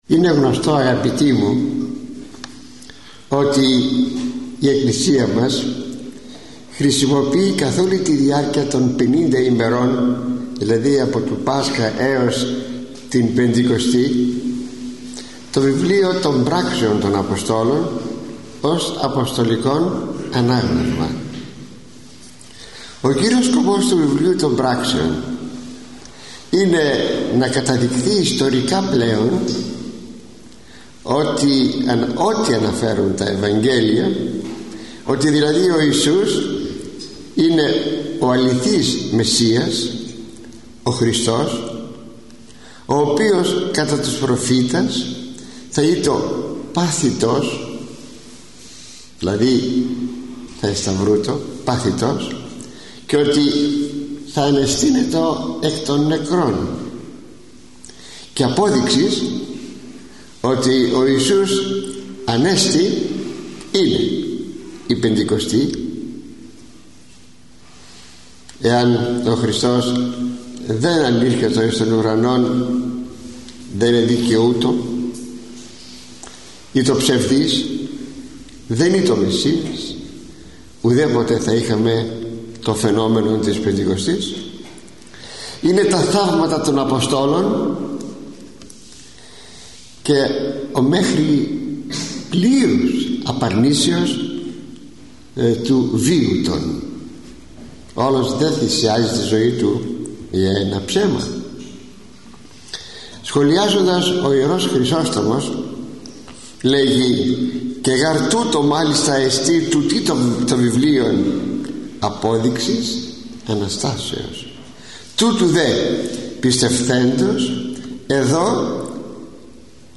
Οι εμφανίσεις του Χριστού μετά την Ανάστασή Του – ηχογραφημένη ομιλία του Μακαριστού Αρχιμ.